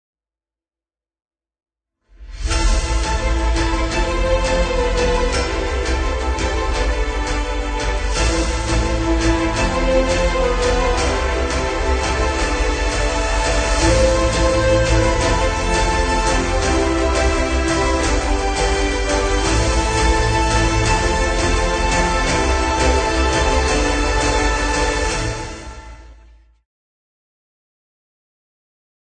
描述：这首史诗般的歌曲非常适合用于预告片、电子游戏和电影。